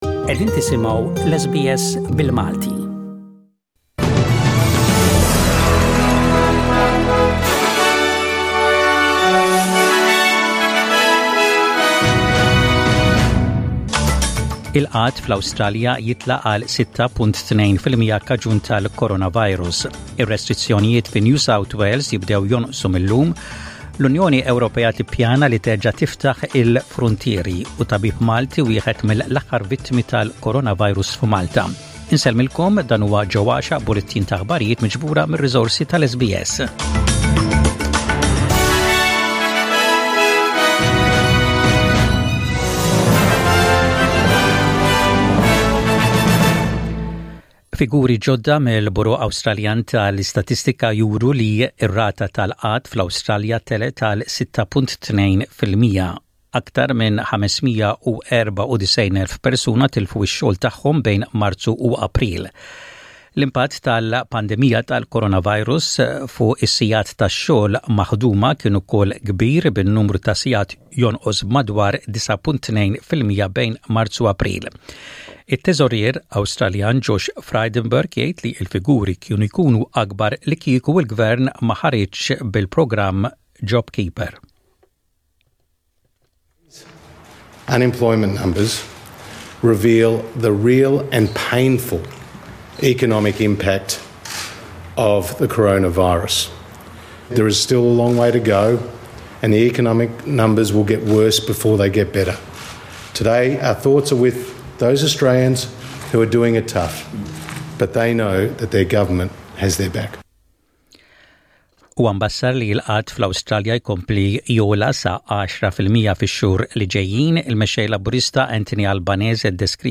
SBS Radio | Maltese News: 16/05/20